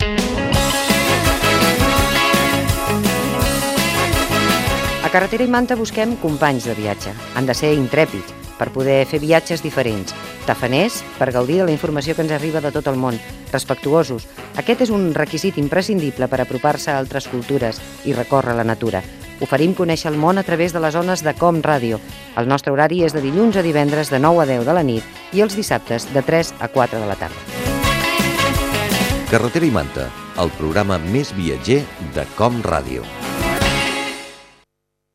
5057eba3dbea4fdb8ce3a23d336d41473dc1d822.mp3 Títol COM Ràdio Emissora COM Ràdio Barcelona Cadena COM Ràdio Titularitat Pública nacional Nom programa Carretera i manta Descripció Promoció del programa.